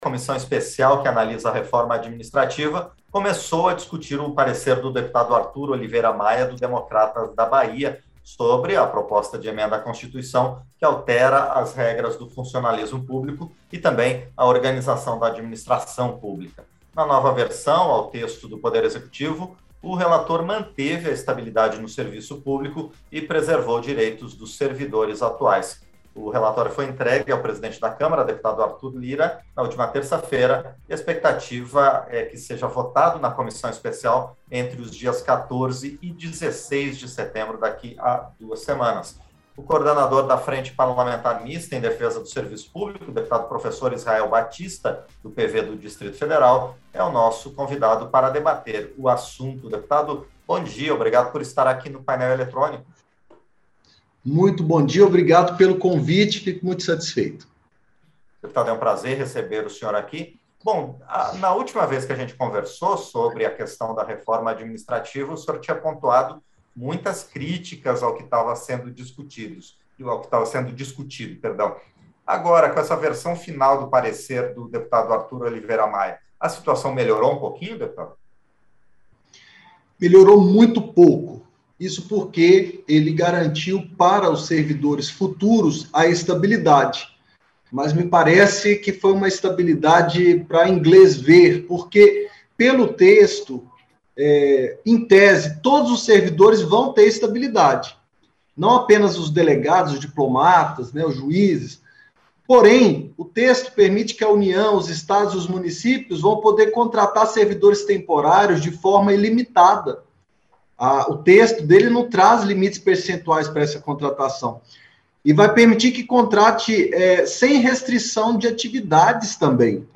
Entrevista - Dep. Professor Israel Batista (PV-DF)